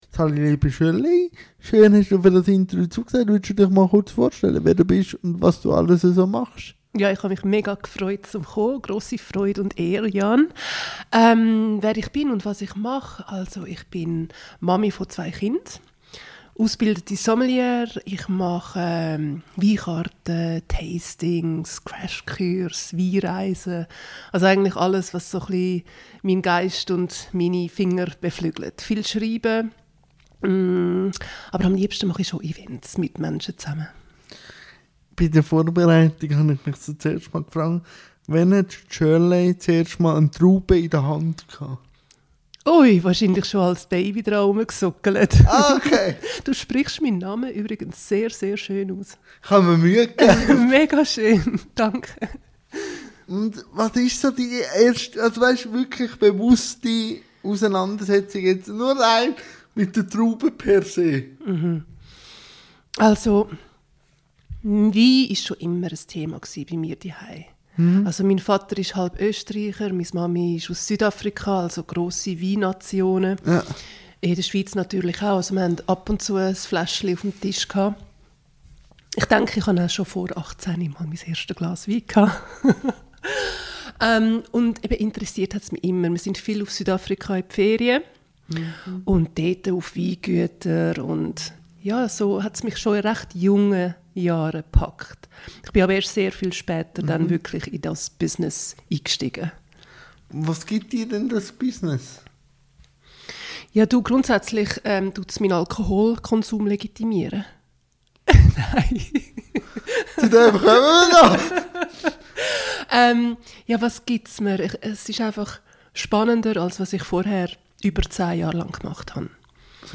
INTERVIEW-THEMA